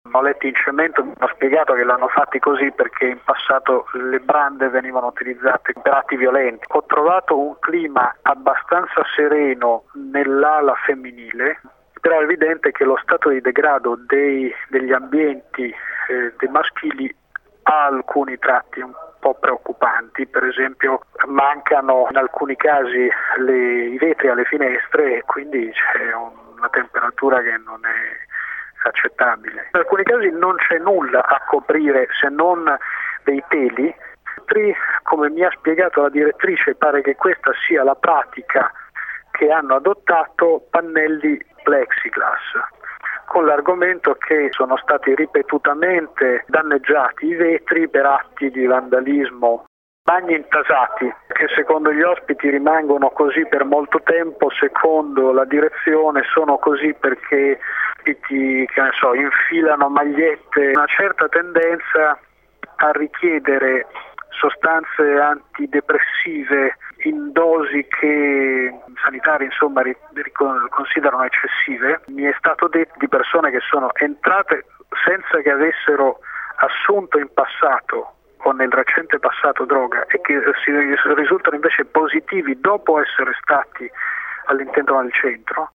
Sentiamo Salvatore Vassallo ai microfoni di Citta del Capo – Radio Metropolitana: